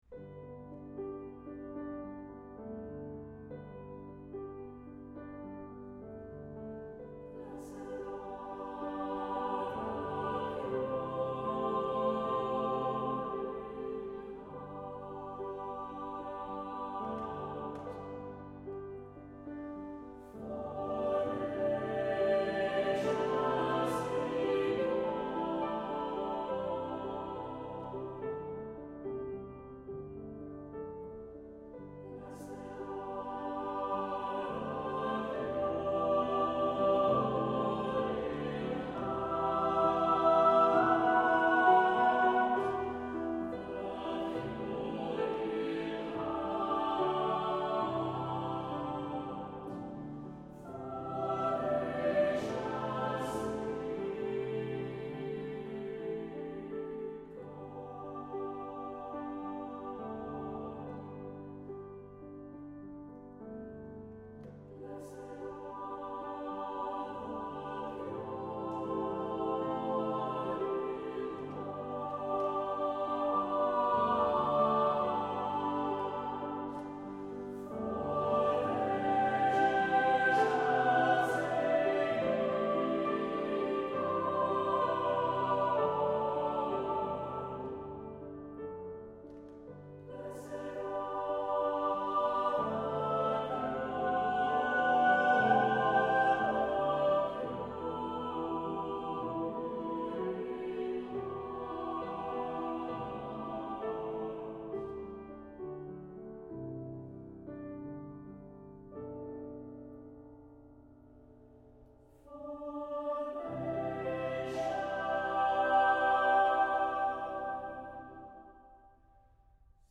Accompaniment:      Piano
Music Category:      Choral